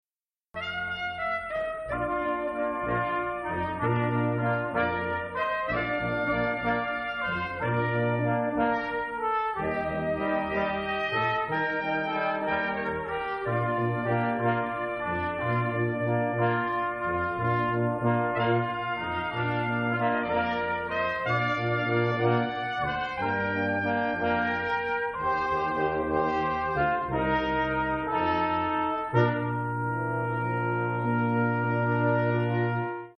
Seton Performance Series - 1/18/2004